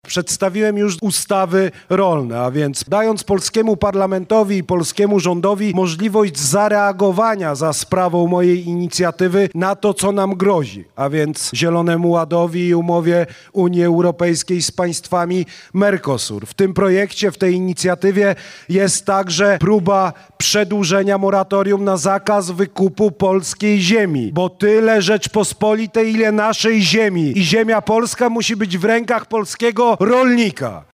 – Będę dla was ciężko pracował i to mogę wam wszystkim obiecać – powiedział prezydent Karol Nawrocki podczas spotkania z mieszkańcami Godziszowa, gdzie w tegorocznych wyborach zdobył ponad 94% głosów poparcia.